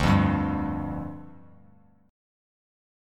Dmbb5 chord